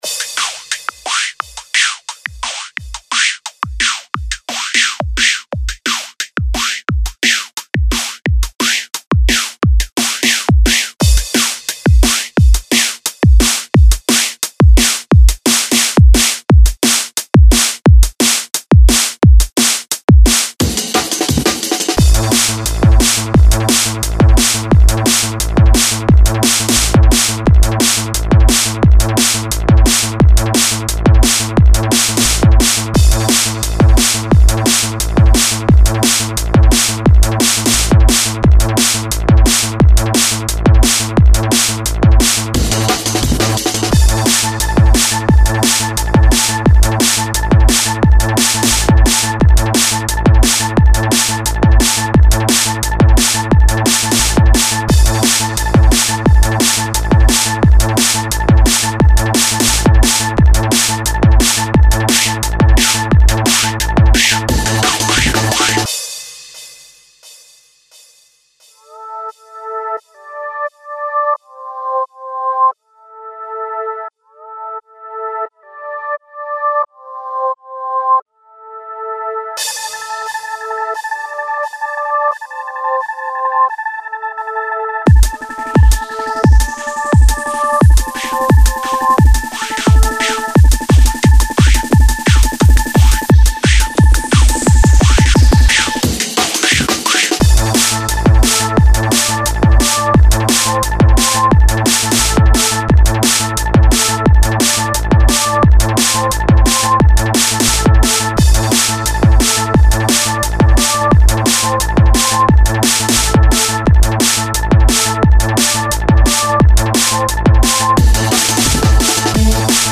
Лёгкий drum n bass, Jump up